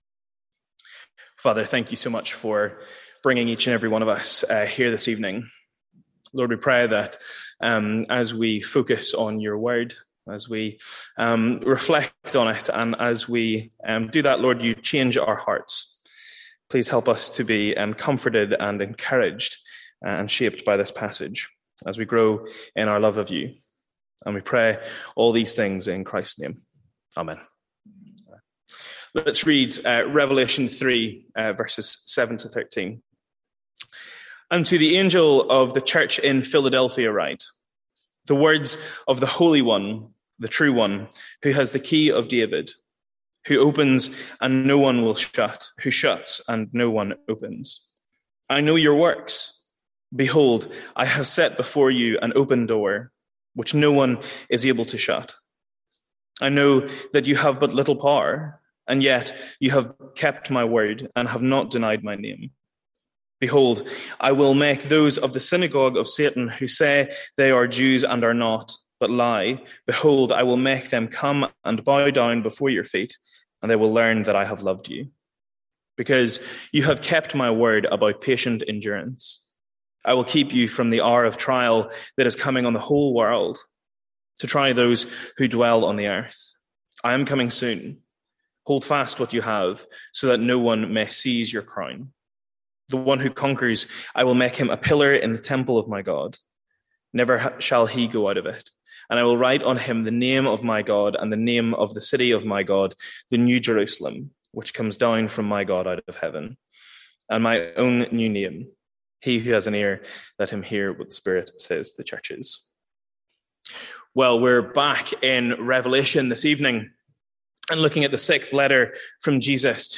Sermons | St Andrews Free Church
From our evening series in Revelation.